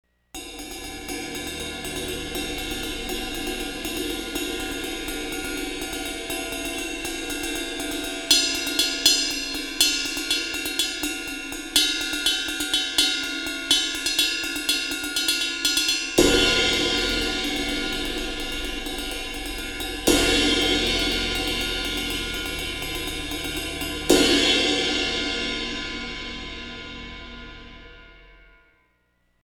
J'ai une A sweet ride en finition traditionnelle, je l'utilise plus en crash rythmique qu'en ride, je trouve qu'en ride pure elle manque un peu de personnalité, elle est entre 2 : pas vraiment un son clair, mais pas un son son dark non plus, pas cristalline mais pas complexe non plus… mais bon c'est sûrement ça la polyvalence :lol:
A SWeet ride 21 trad
La cloche passe bien, mais sinon en ride, le ping est très timide (pour les musique calmes ça va bien).
Une ride très douce quoi. :)